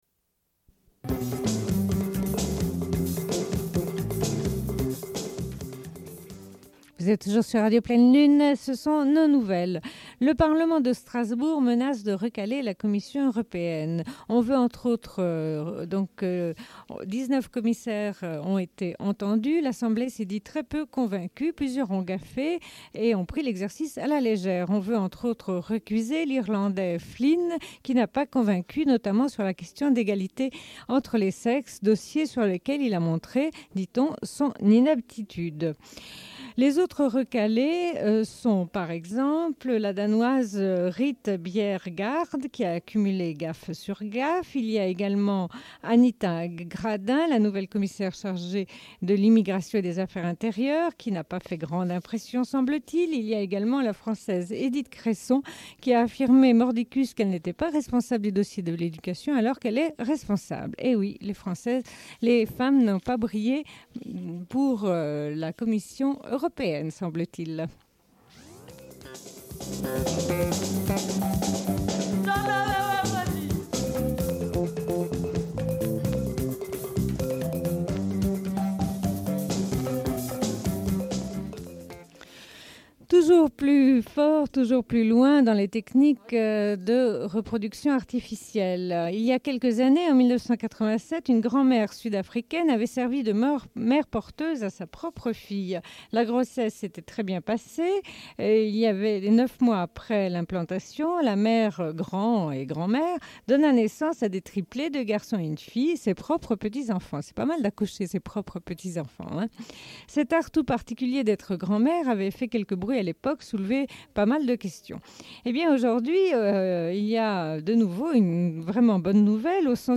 Bulletin d'information de Radio Pleine Lune du 18.01.1995 - Archives contestataires
Une cassette audio, face B29:43